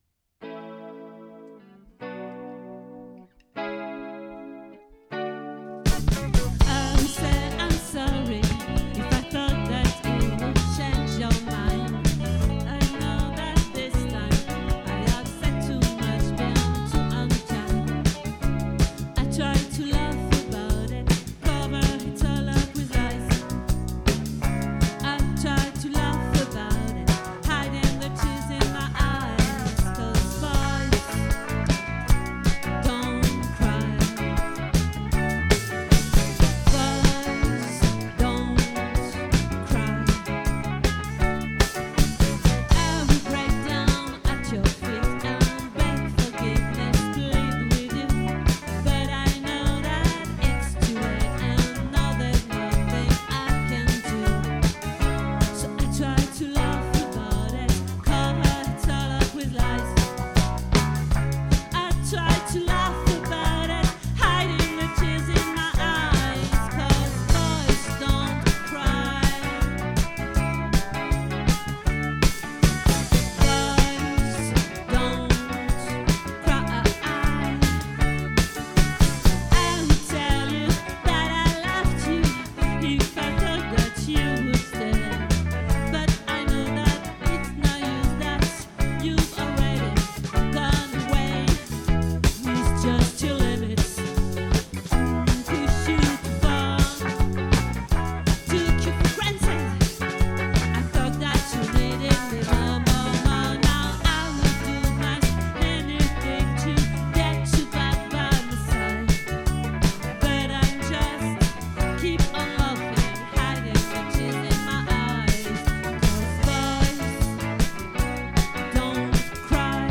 🏠 Accueil Repetitions Records_2025_09_01